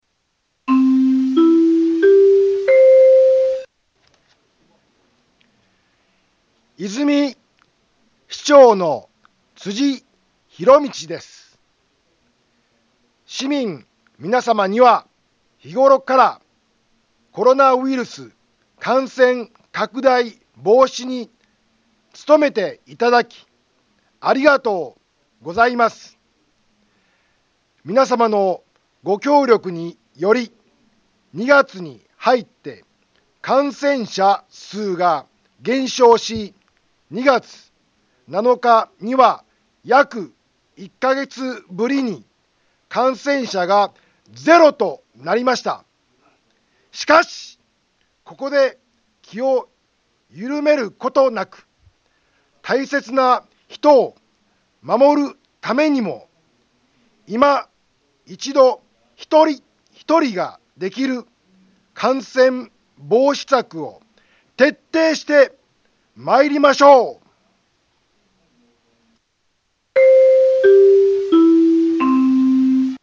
Back Home 災害情報 音声放送 再生 災害情報 カテゴリ：通常放送 住所：大阪府和泉市府中町２丁目７−５ インフォメーション：和泉市長の、辻 ひろみちです。